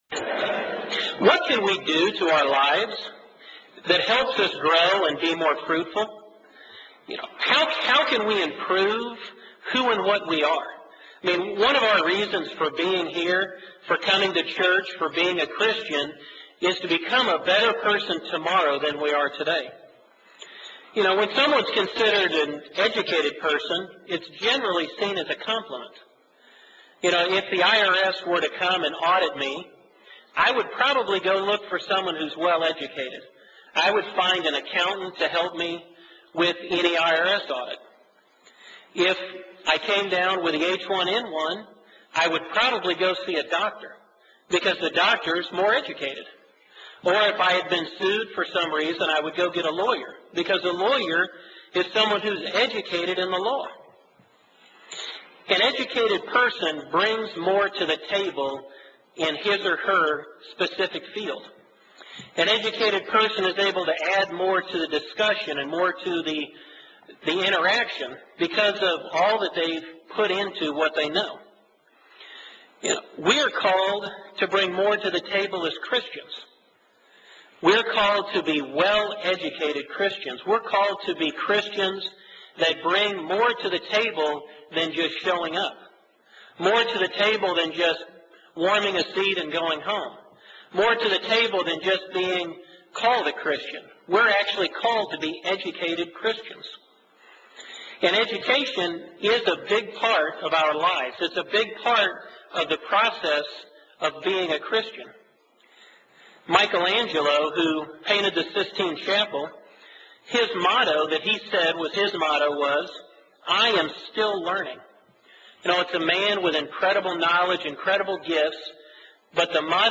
Given in Columbus, OH
UCG Sermon Studying the bible?